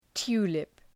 {‘tu:lıp}